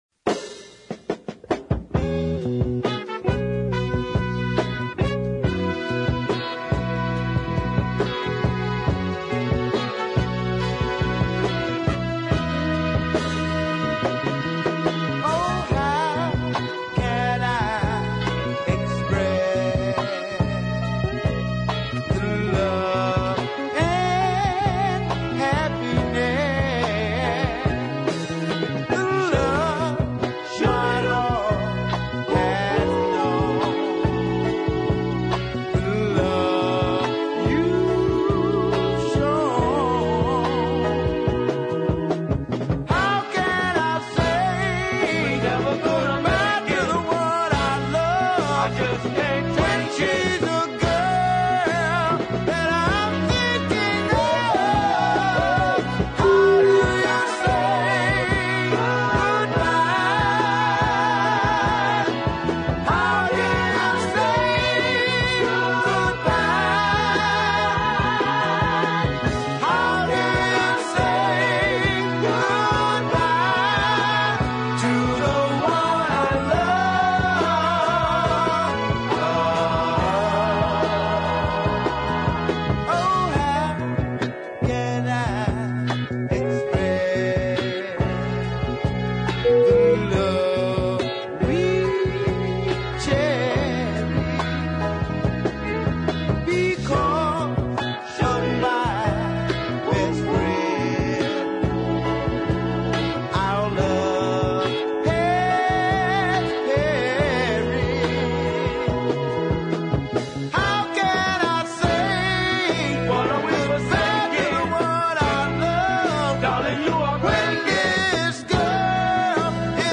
gentle beat ballad